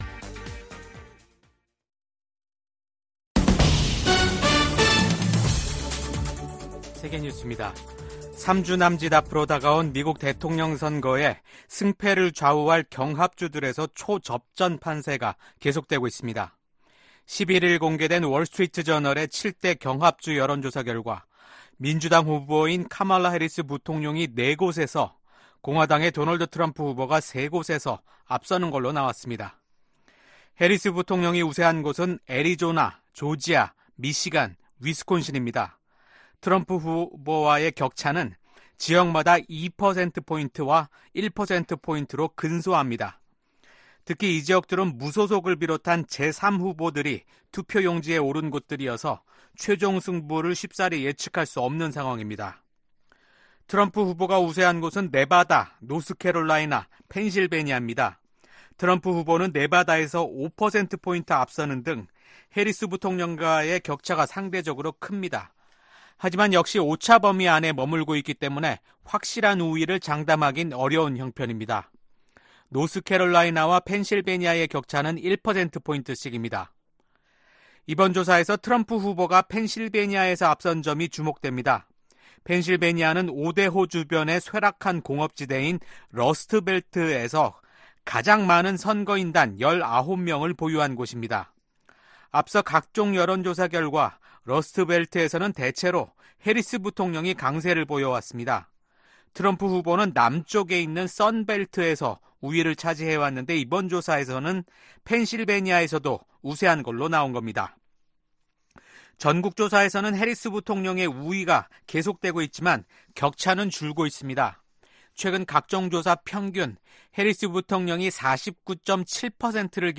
VOA 한국어 아침 뉴스 프로그램 '워싱턴 뉴스 광장' 2024년 10월 12일 방송입니다. 한국 작가로는 최초로 소설가 한강 씨가 노벨문학상 수상자로 선정됐습니다. 윤석열 한국 대통령은 동아시아 정상회의에서 북한과 러시아의 불법적 군사 협력을 정면으로 비판했습니다.